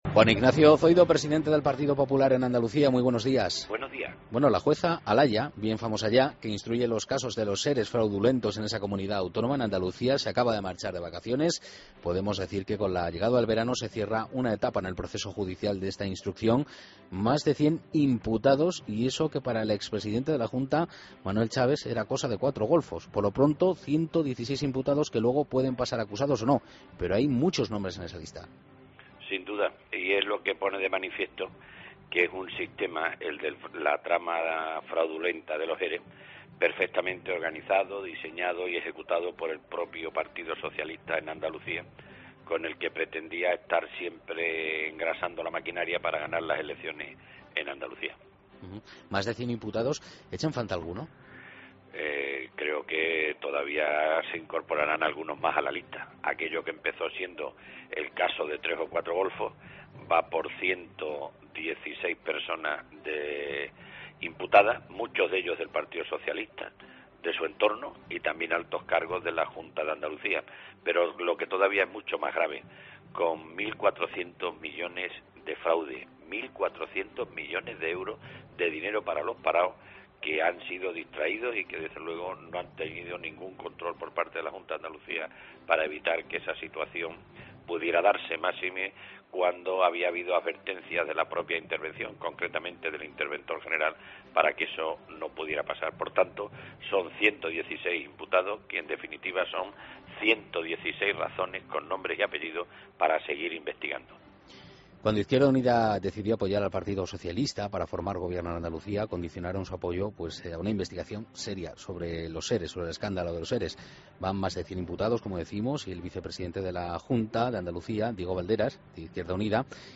Entrevista a Juan Ignacio Zoido, presidente del PP en Andalucía